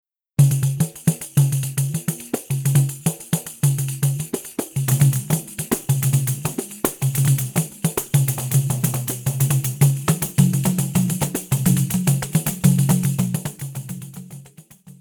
Choro brasileiro